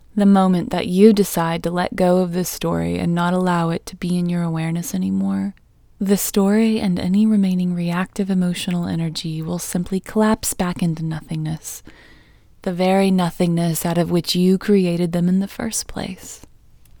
OUT Technique Female English 28